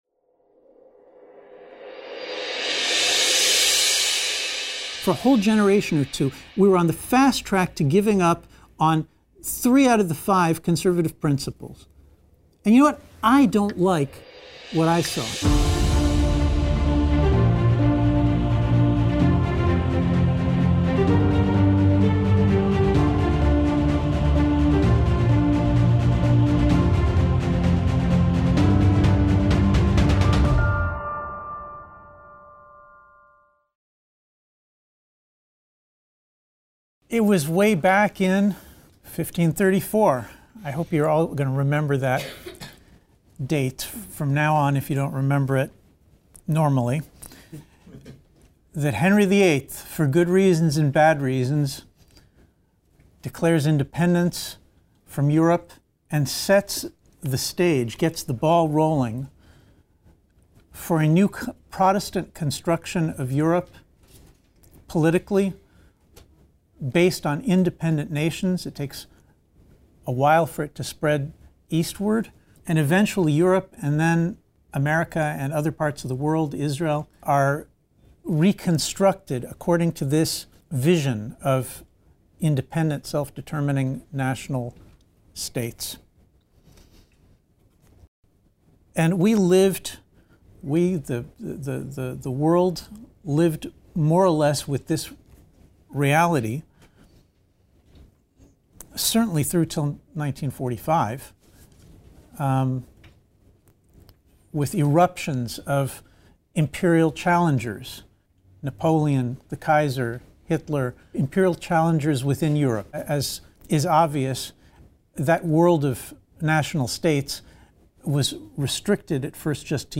In his concluding lecture, Dr. Hazony details the key principles of Anglo-American conservatism and defends the remarkable contributions of English, American, and Jewish nationalism.